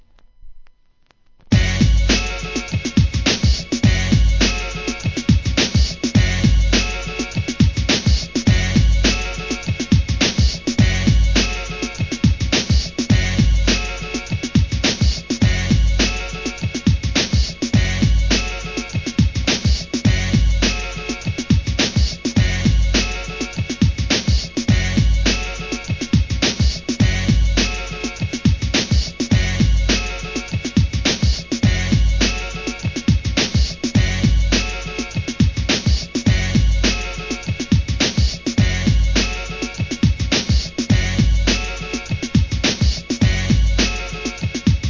HIP HOP/R&B
98 BPM